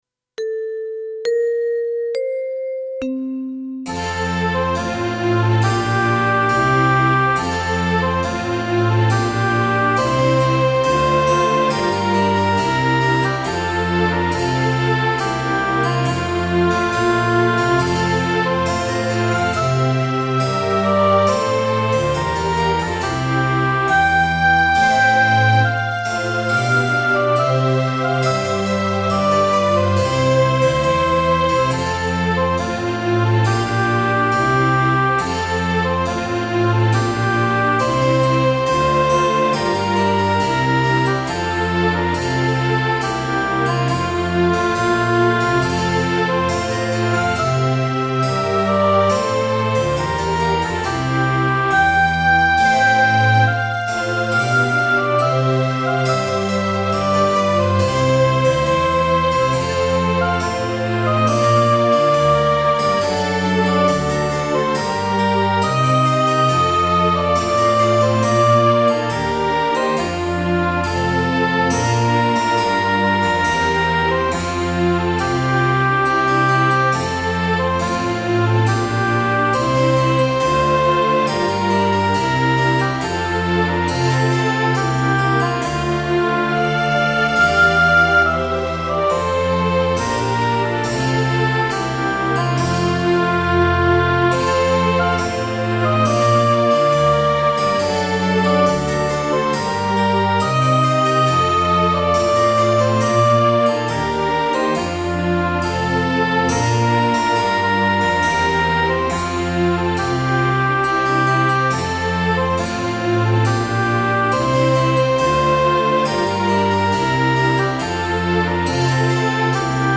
Un'Aria dal profilo melodico molto elegante, su un andamento ritmico grazioso e solenne allo stesso tempo.